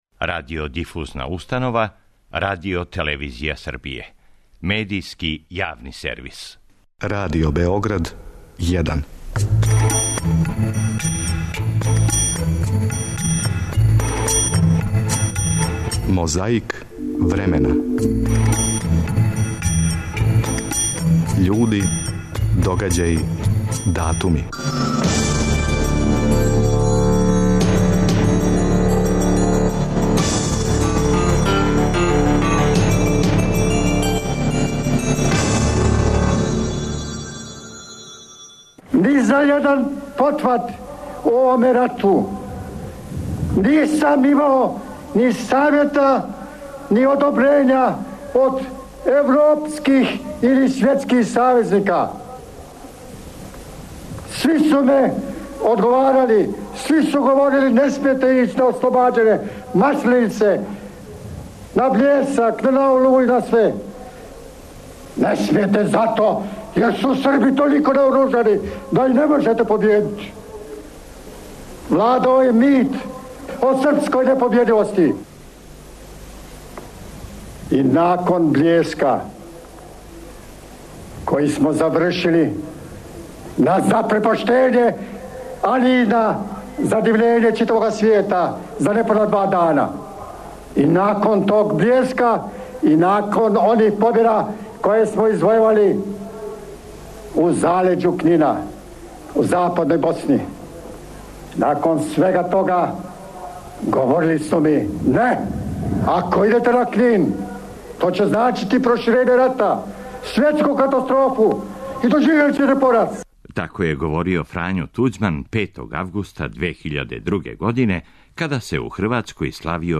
У Хрватској се 5. августа 2002. године славио Дан победе, а данашњу борбу против пилећег памћења почињемо подсећањем на говор, који је тим поводом, одржао Фрањо Туђман.